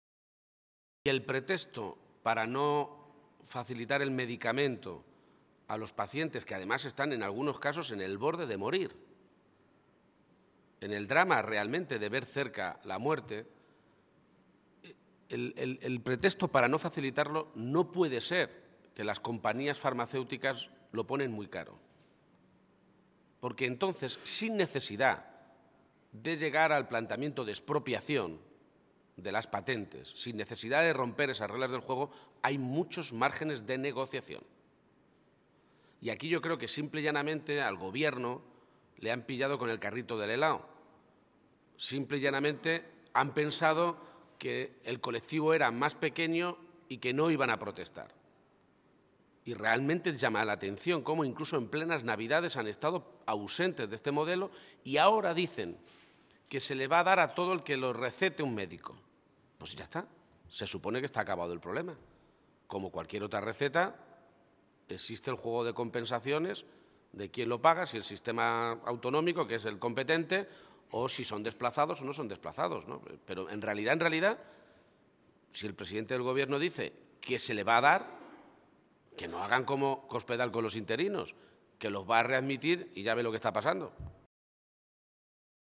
García-Page se pronunciaba de esta manera esta mañana, en Toledo, en una comparecencia ante los medios de comunicación que ha tenido un gran contenido relacionado con asuntos sanitarios.
Cortes de audio de la rueda de prensa